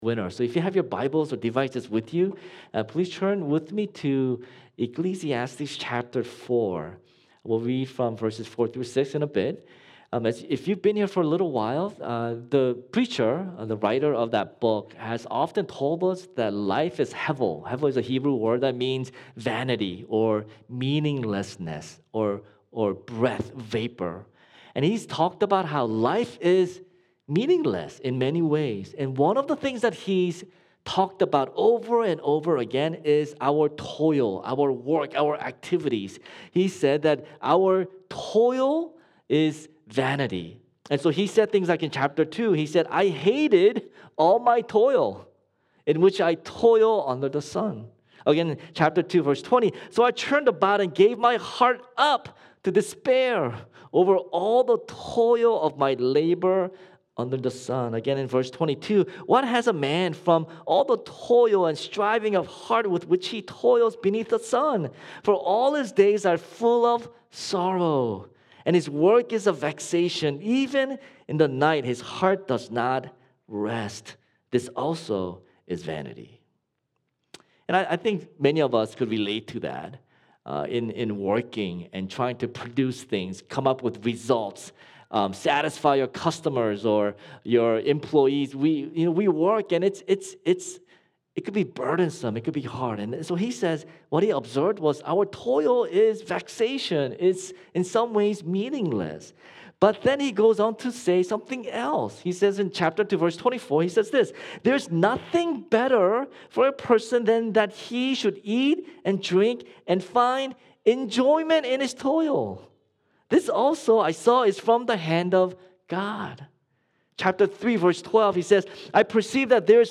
February-6-Sermon-Tysons.mp3